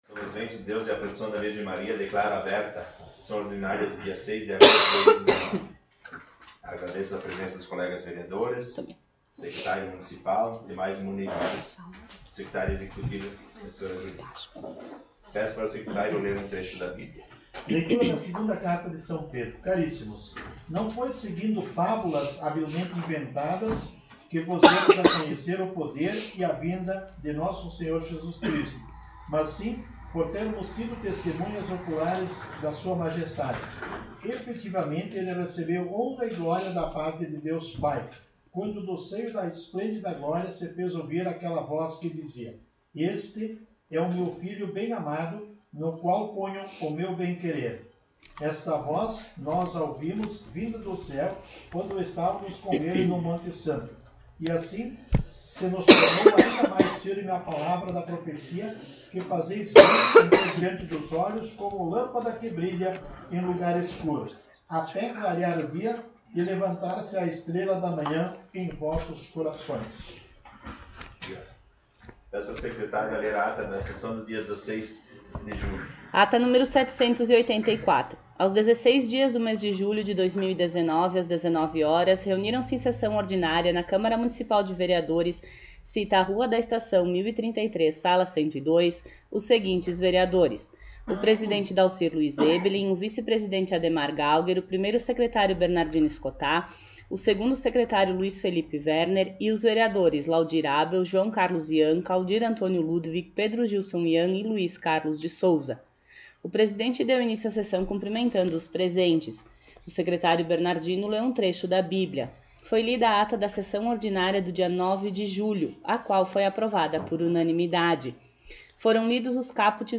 Sessão Ordinária 06 de Agosto de 2019